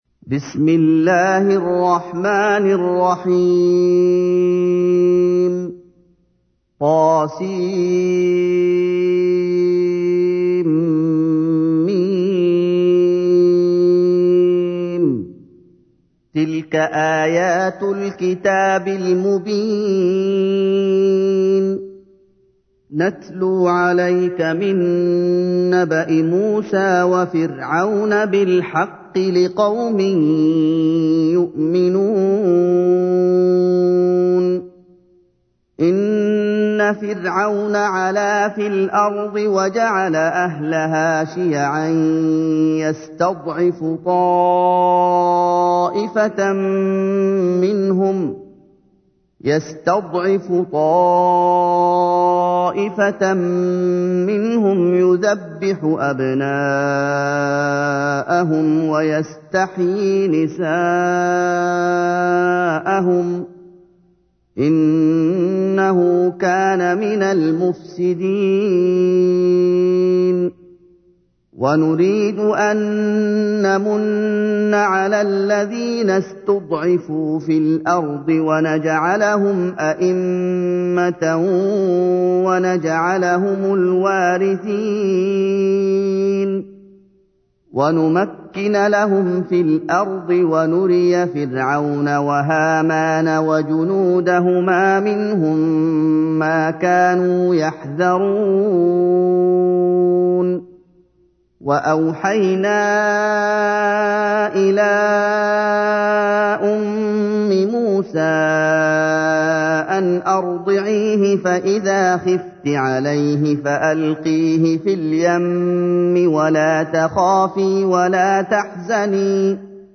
تحميل : 28. سورة القصص / القارئ محمد أيوب / القرآن الكريم / موقع يا حسين